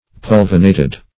Search Result for " pulvinated" : The Collaborative International Dictionary of English v.0.48: Pulvinate \Pul"vi*nate\, Pulvinated \Pul"vi*na`ted\, a. [L. pulvinatus, fr. pulvinus a cushion, an elevation.] 1.
pulvinated.mp3